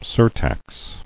[sur·tax]